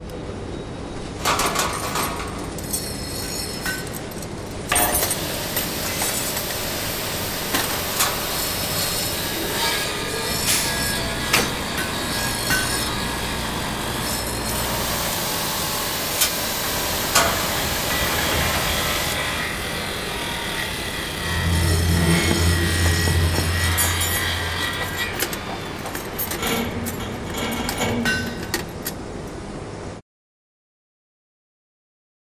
Sound design demos
Industrial soundscape